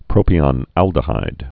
(prōpē-ŏn-ăldə-hīd)